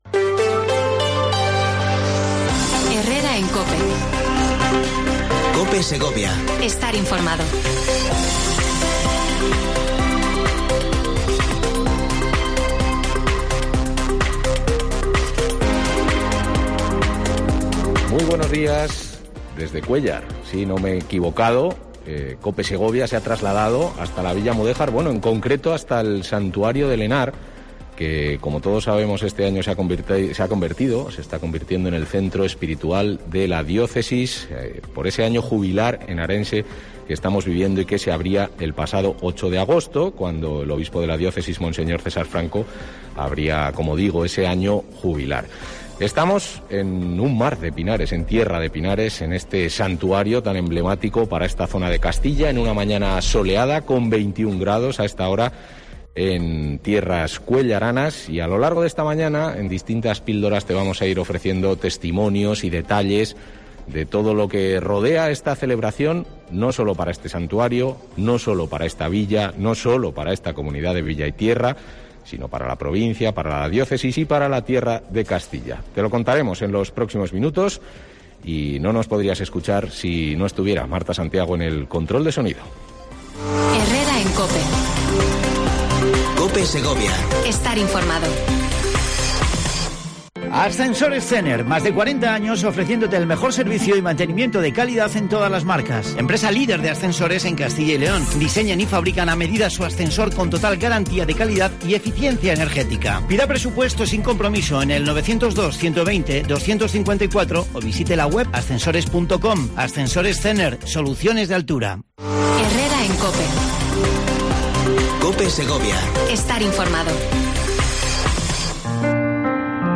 PROGRAMA ESPECIAL AÑO JUBILAR HENARENSE DESDE EL SANTUARIO VIRGEN DE EL HENAR I